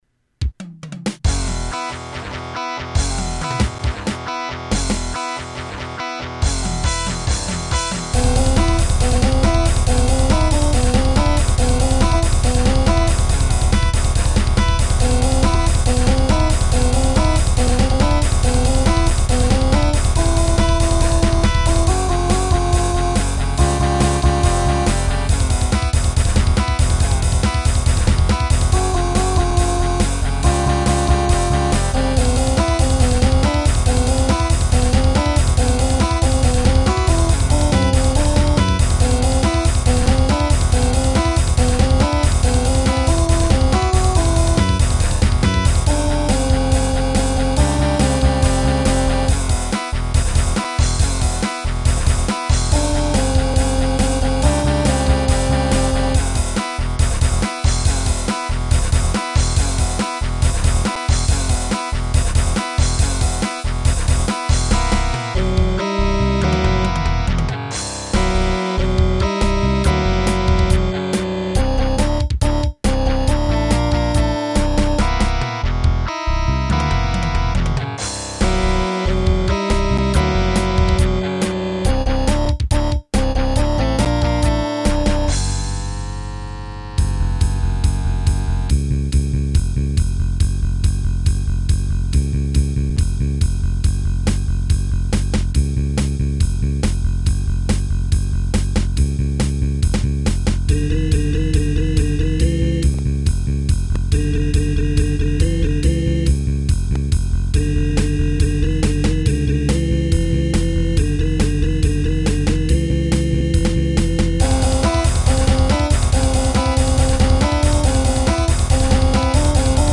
Y estan en MIDI, algun dia estaran grabadas.